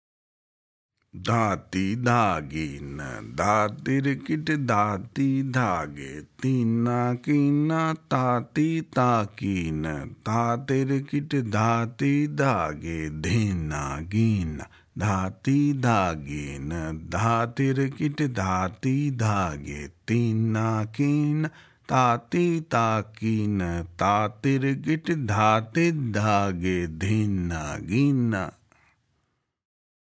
Demonstrations
Spoken – Slow